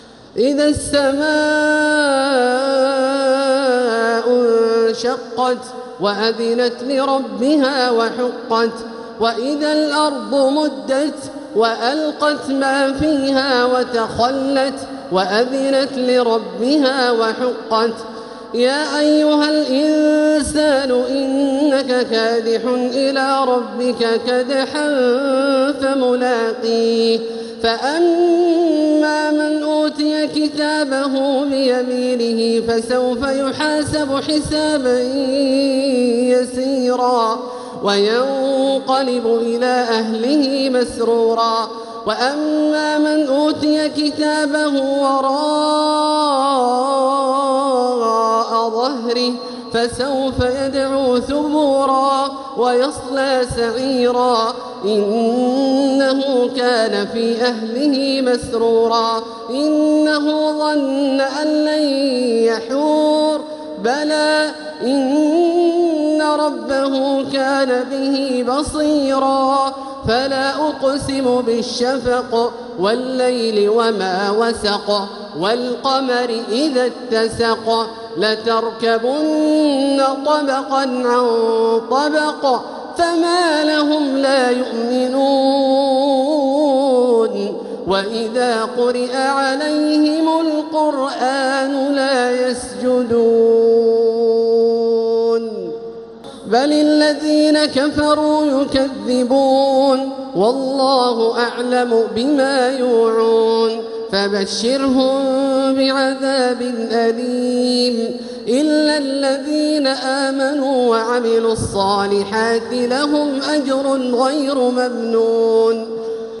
سورة الانشقاق | مصحف تراويح الحرم المكي عام 1446هـ > مصحف تراويح الحرم المكي عام 1446هـ > المصحف - تلاوات الحرمين